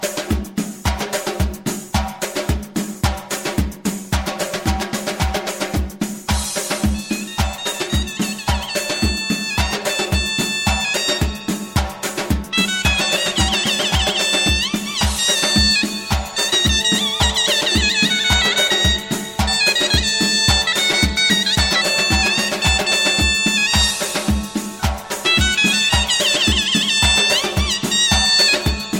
Dance azerbaycan